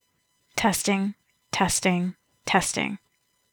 Effect > Noise Reduction: 18, 0, 200, 0
Effect > High Pass Filter: 100Hz 24dB
Effect > Amplify to -1.